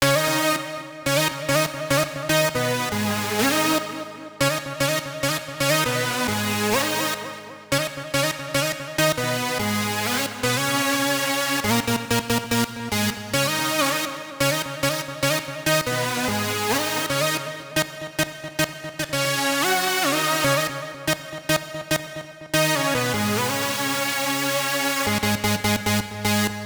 05 lead A.wav